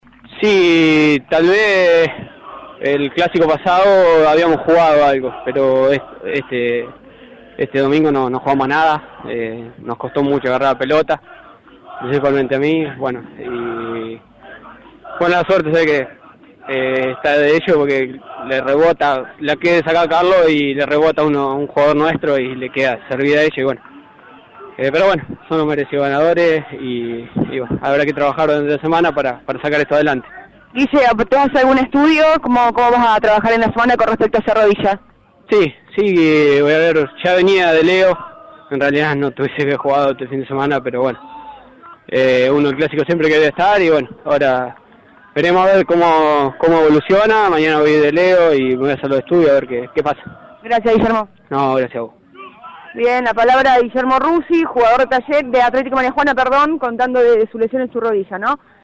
Fue transmisión de la radio